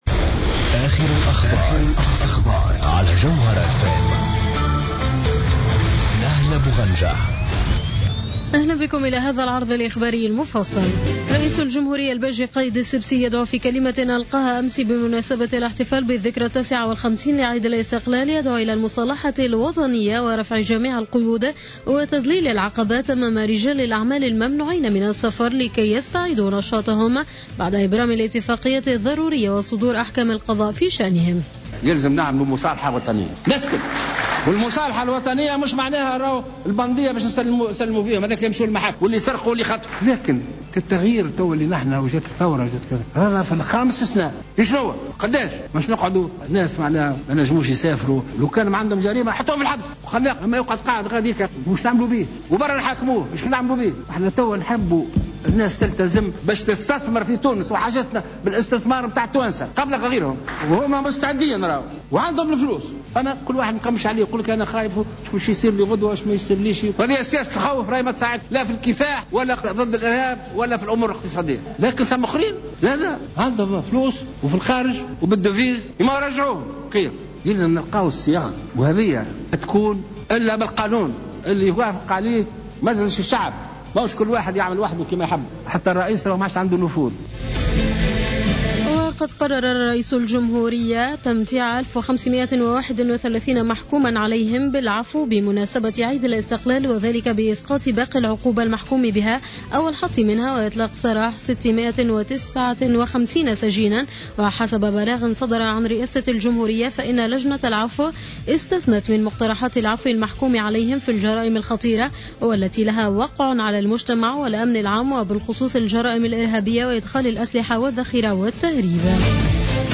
نشرة أخبار منتصف الليل ليوم السبت 21 مارس2015